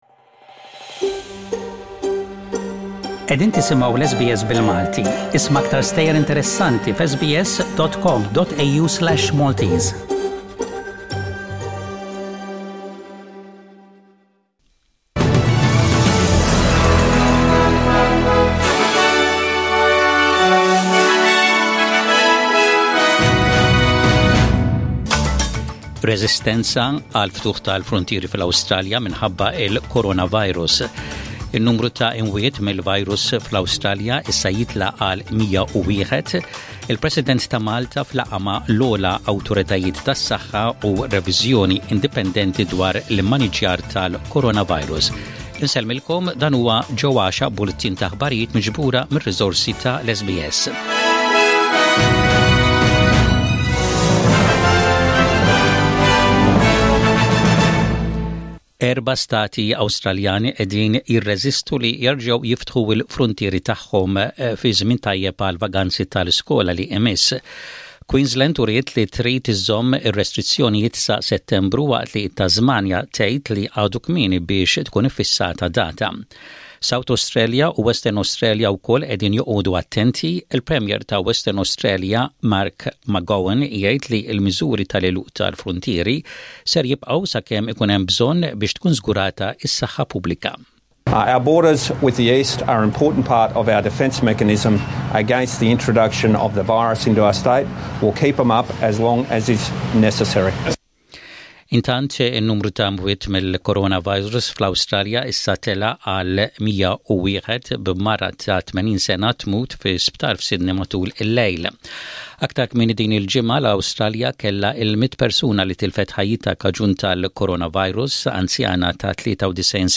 SBS Radio | Maltese News: 22/05/20